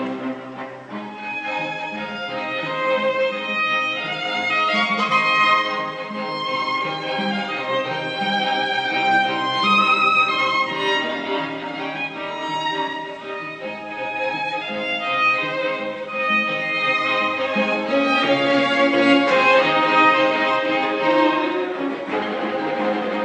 : F 장조, 2/4 박자, 대 론도 형식(A-B-A-C-A-B-A)이다.
주요 멜로디는 오음계이다.